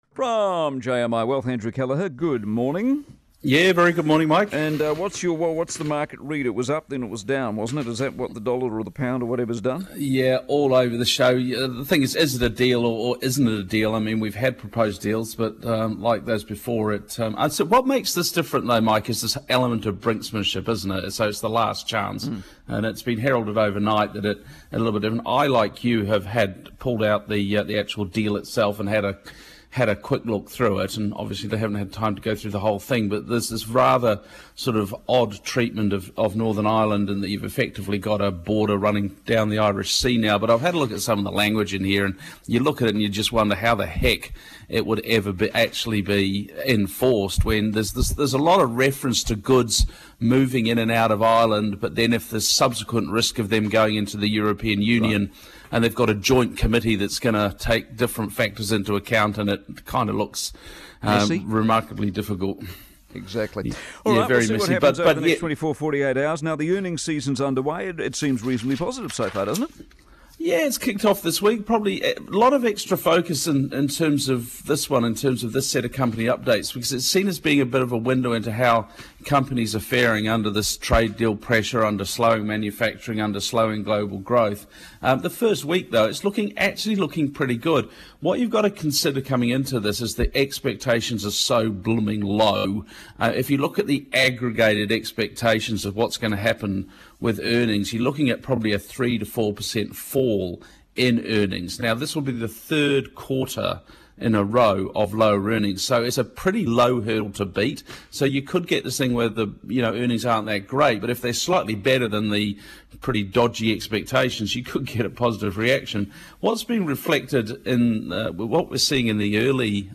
Newstalk ZB Commentary | Select Wealth